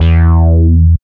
70.01 BASS.wav